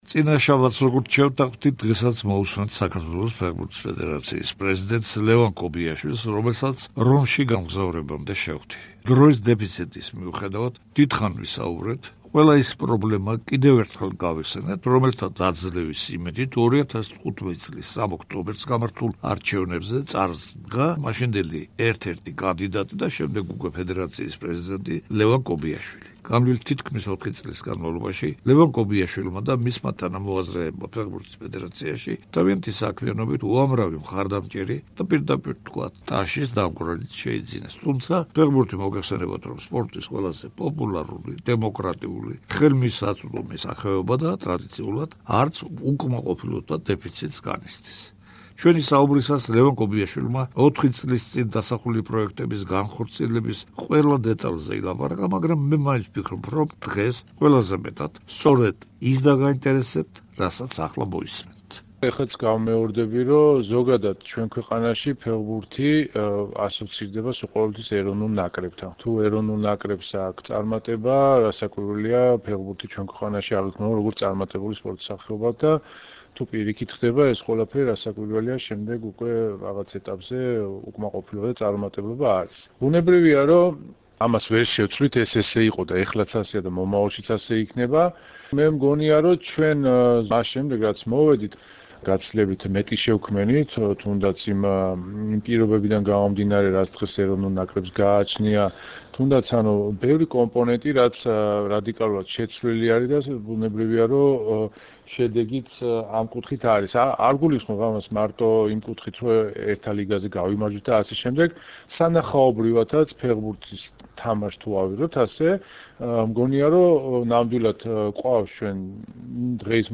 საუბარი ლევან კობიაშვილთან